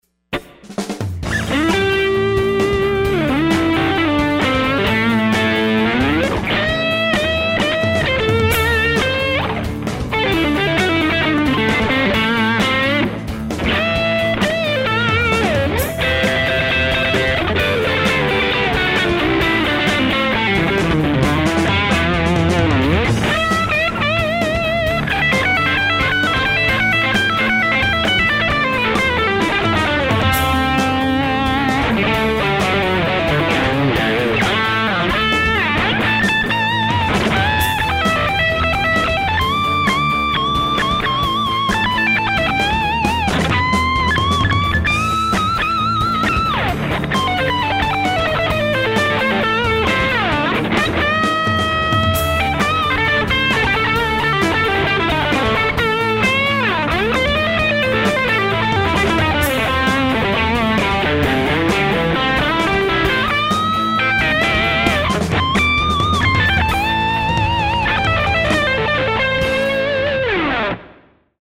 I just made a quick *ghetto* video of me playing over a jam track from my "Lessons From the Masters" series.
What's cool about this is that, based on a lot of feedback I've had recently, a lot of you are into more blues/rock than the old school blues, and this is DEFINITELY in that spirit.
It's loud and fast, and I think you'll have a lot of fun with it.